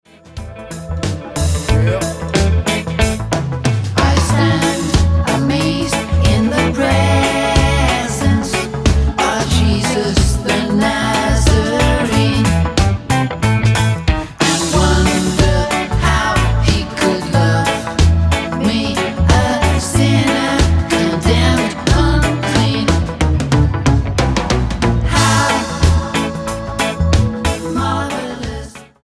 STYLE: Rock
wispy vocals are bolstered by layered background vocals